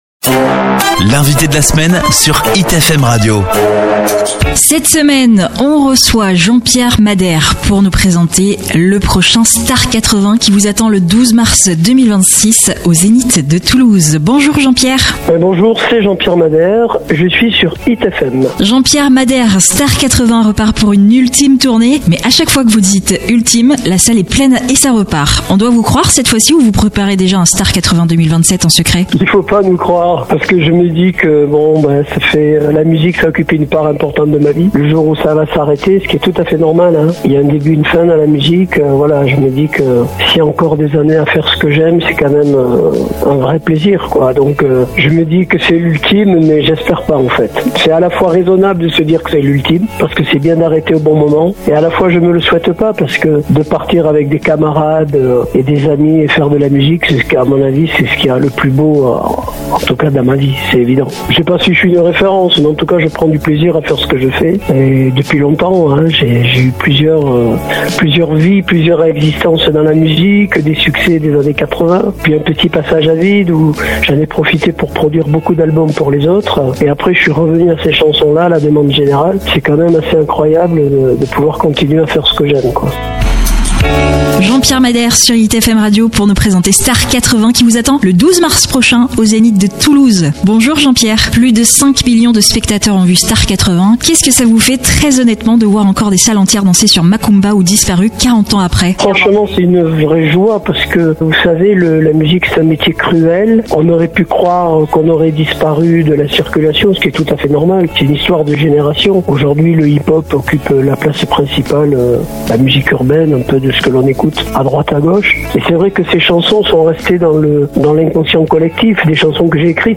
L’entretien explore aussi son rapport particulier à Toulouse, sa ville, où l’émotion de “jouer à la maison” reste incomparable même si il avoue que la pression est plus grande et intense.